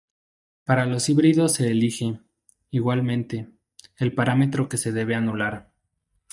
/iˌɡwalˈmente/